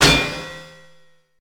slam.ogg